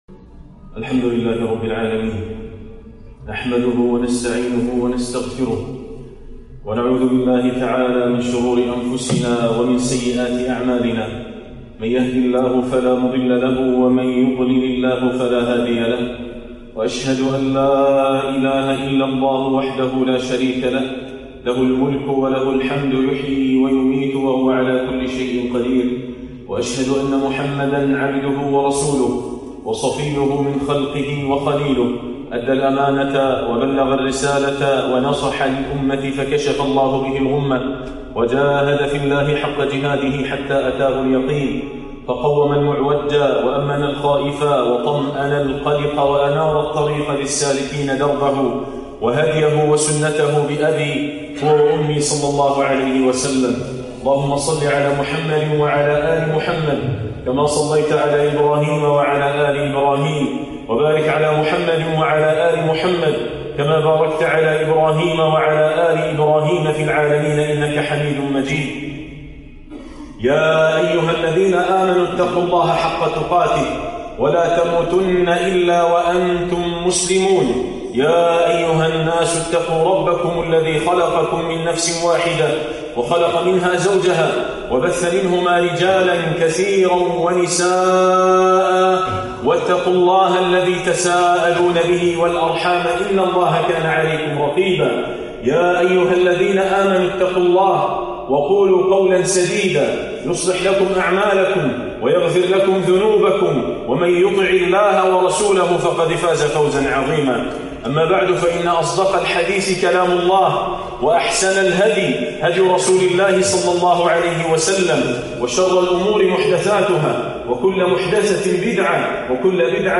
نعمة الأخوة بين المسلمين - خطبة